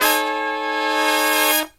LONG HIT05-R.wav